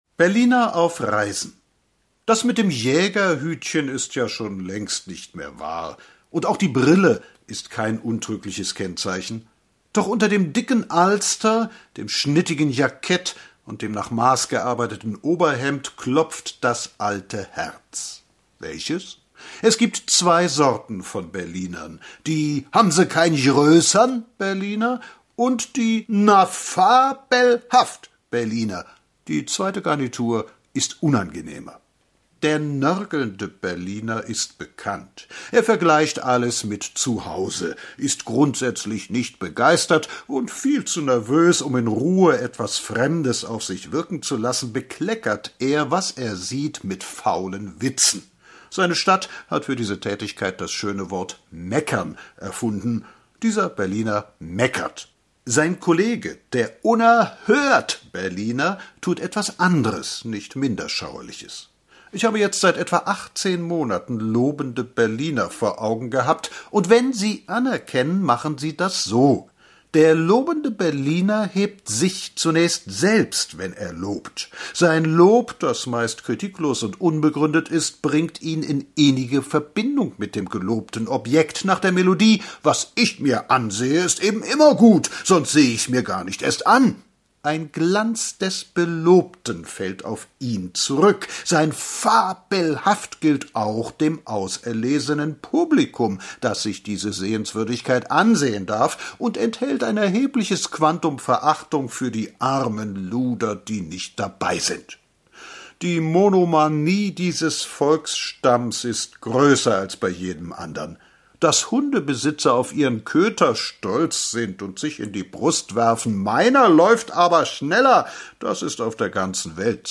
Gelesen